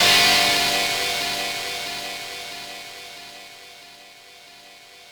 ChordG7.wav